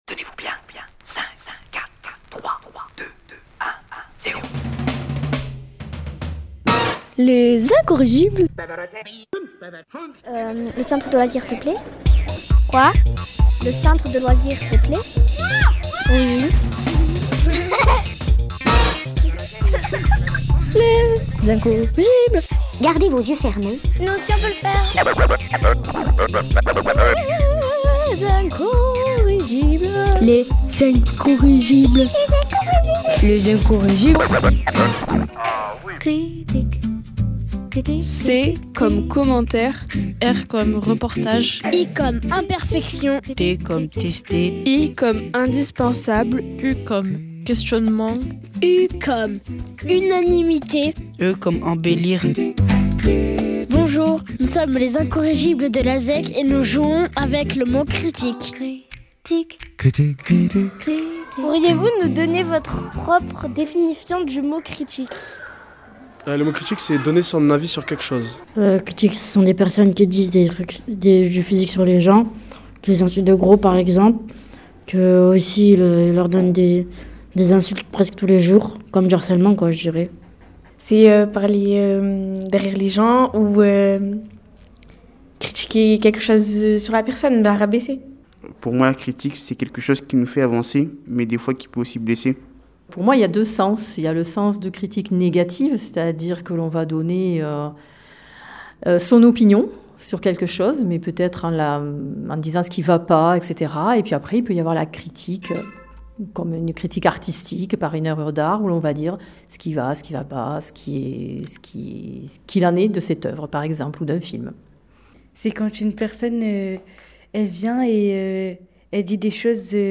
Microtrottoirs, poèmes, acrostiches et entretiens...